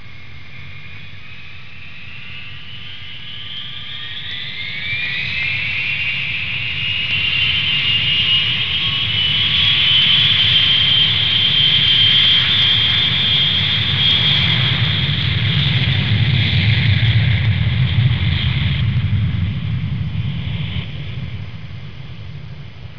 دانلود آهنگ طیاره 16 از افکت صوتی حمل و نقل
دانلود صدای طیاره 16 از ساعد نیوز با لینک مستقیم و کیفیت بالا
جلوه های صوتی